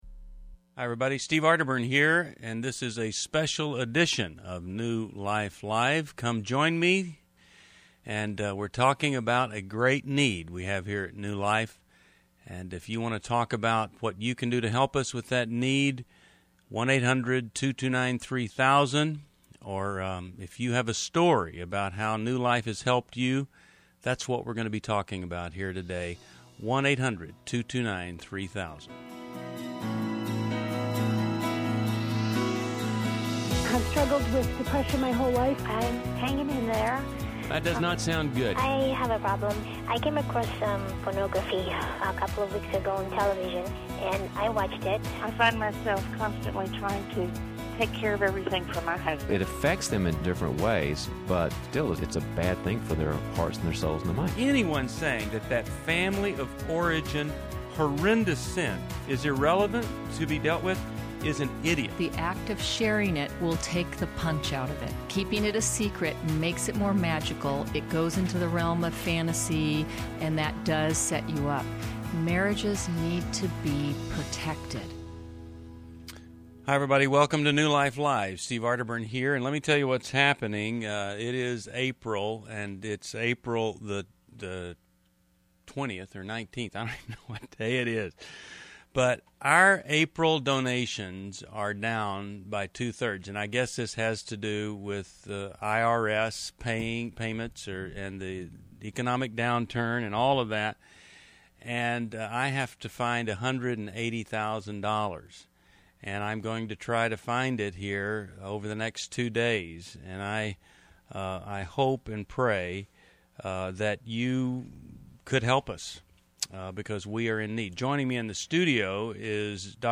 Caller Questions: Why I want to keep New Life Live on the air!